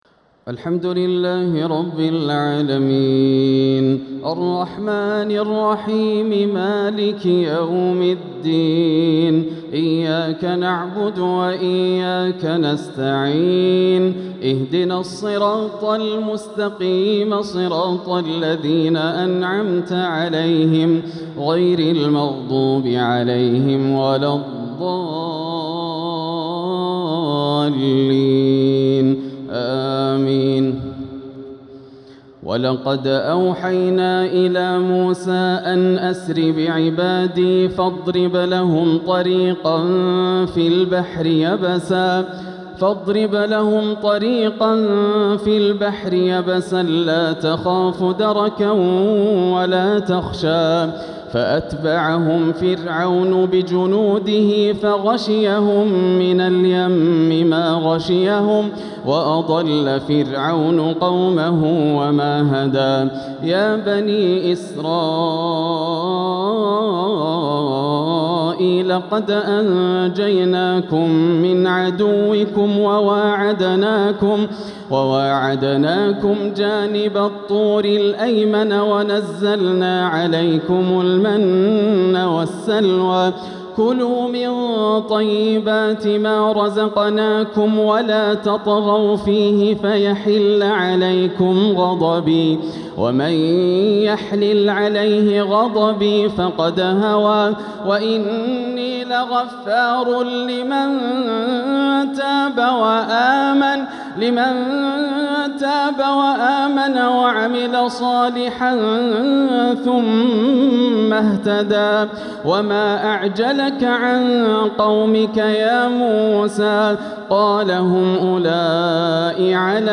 تهجد ليلة 21 رمضان 1447هـ من سورتي طه (77-135) و الأنبياء (1-47) > الليالي الكاملة > رمضان 1447 هـ > التراويح - تلاوات ياسر الدوسري